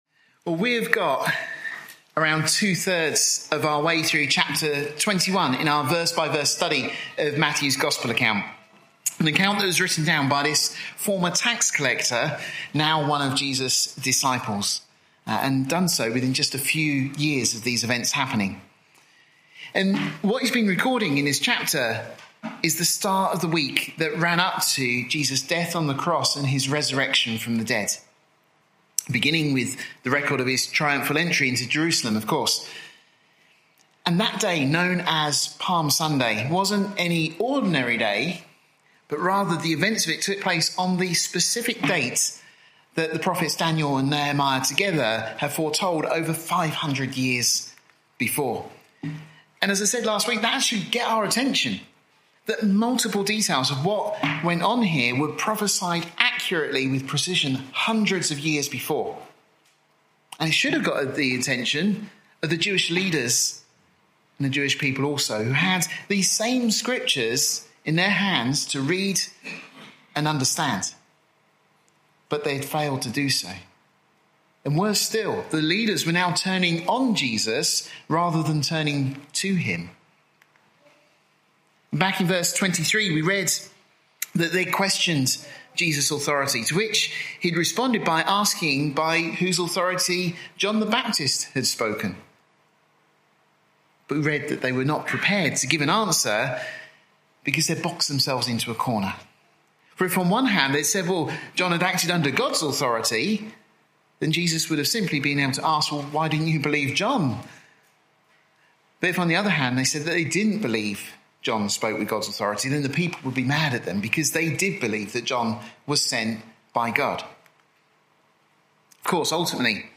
This study is part of our series of verse by verse studies of Matthew, the 40th book in the Bible.